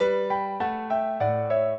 piano
minuet14-9.wav